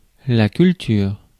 Ääntäminen
IPA: [kyl.tyʁ]